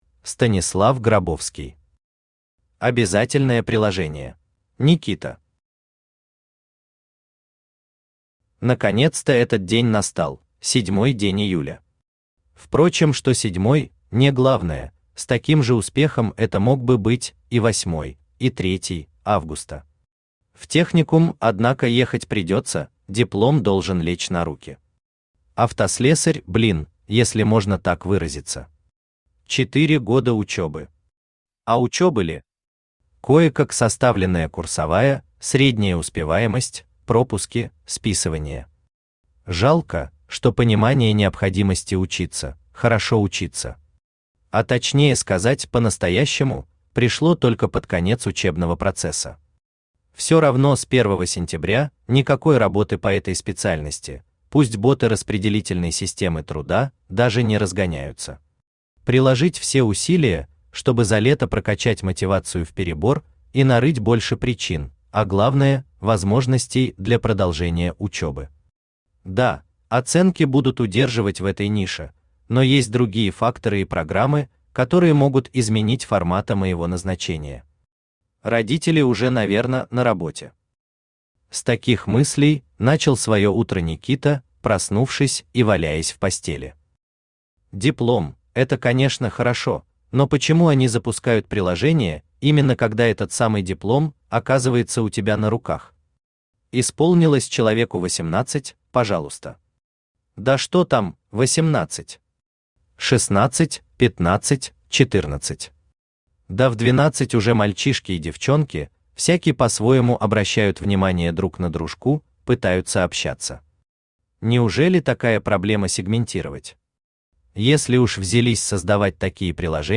Аудиокнига Обязательное приложение | Библиотека аудиокниг
Aудиокнига Обязательное приложение Автор Станислав Грабовский Читает аудиокнигу Авточтец ЛитРес.